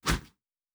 pgs/Assets/Audio/Fantasy Interface Sounds/Whoosh 05.wav
Whoosh 05.wav